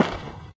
pedology_silt_footstep.5.ogg